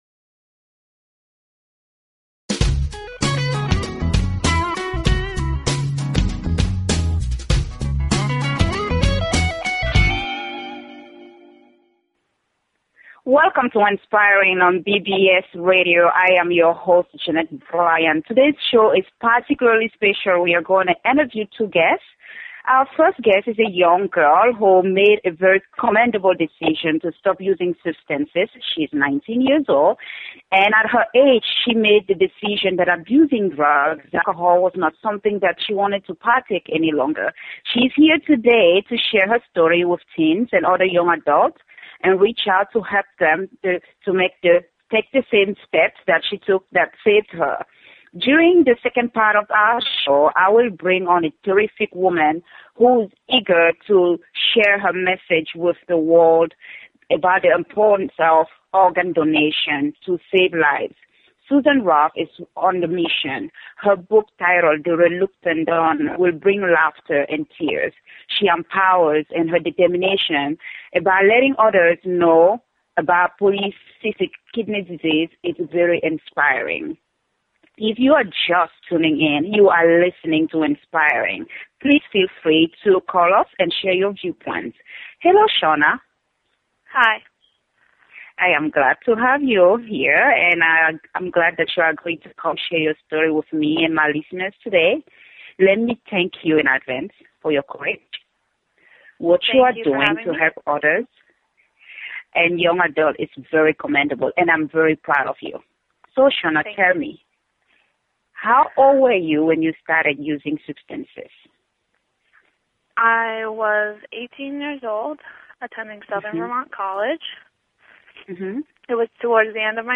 Talk Show Episode, Audio Podcast, Inspiring and Courtesy of BBS Radio on , show guests , about , categorized as
I interviewed two guests.